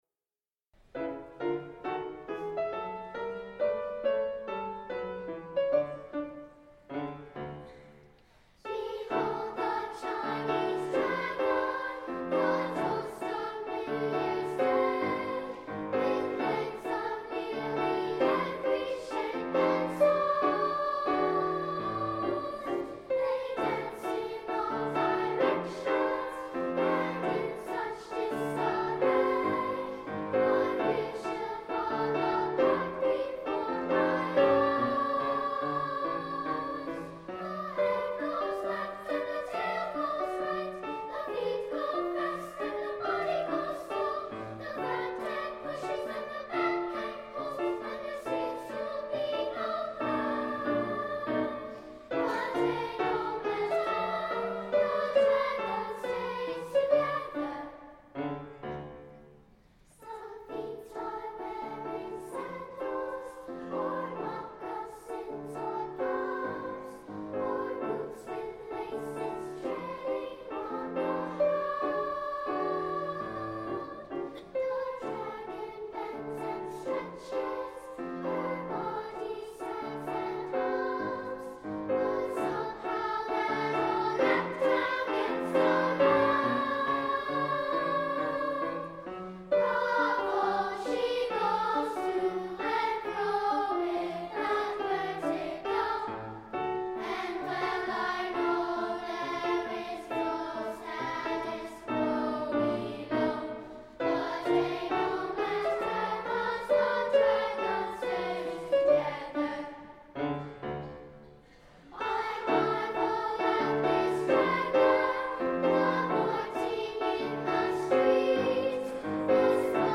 SA, piano